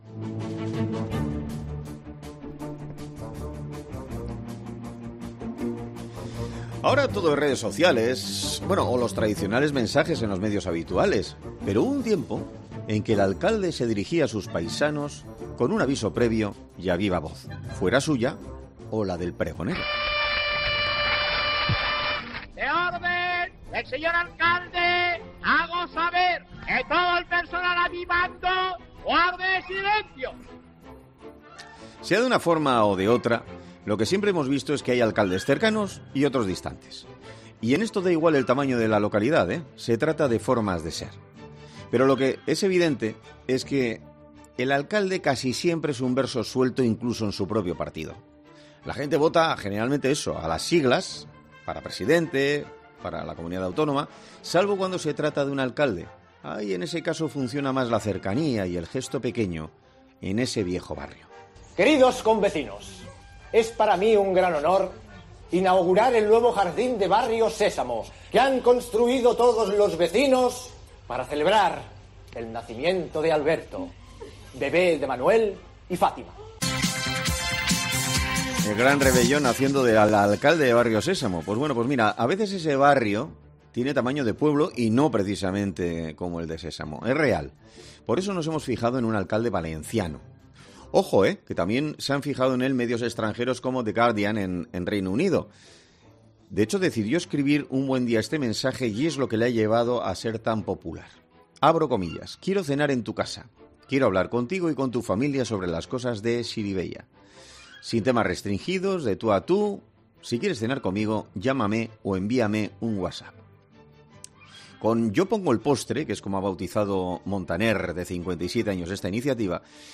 En 'Herrera en COPE' hablamos con Michel Montaner, alcalde de Xirivella sobre su idea "Yo pongo el postre"